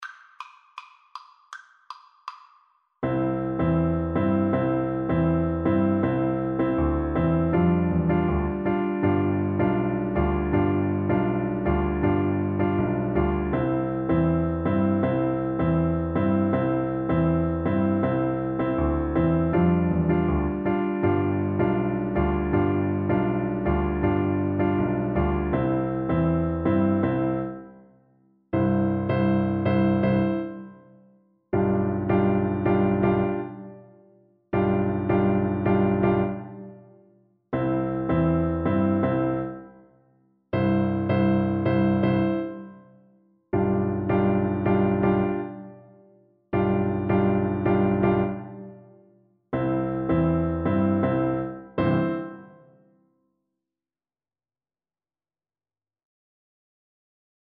Xylophone
C major (Sounding Pitch) (View more C major Music for Percussion )
G6-C8
4/4 (View more 4/4 Music)
Presto (View more music marked Presto)
World (View more World Percussion Music)